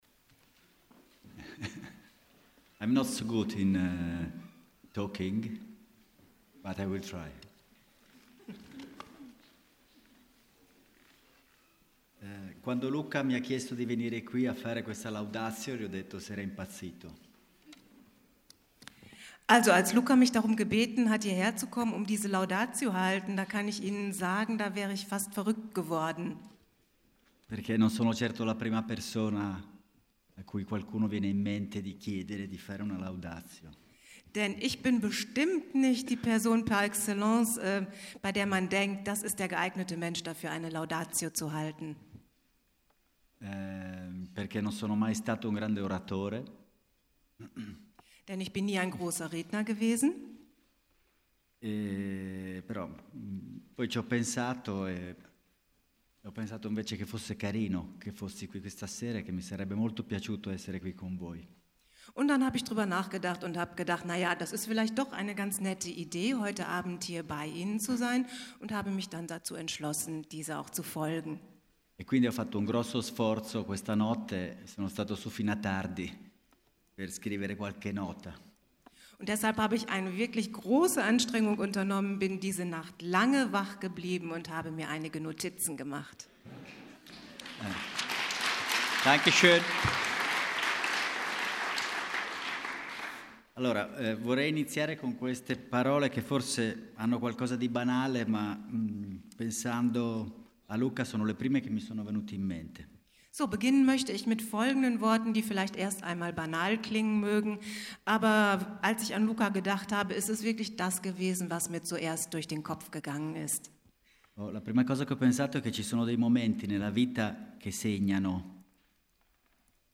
2017 - Laudatio von Silvio Soldini bei der Preisverleihung an Luca Bigazzi